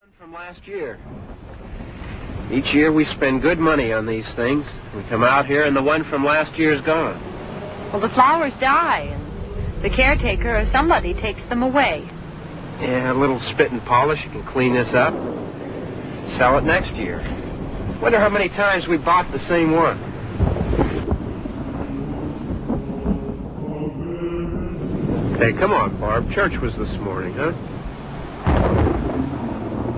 Halloween soundscapes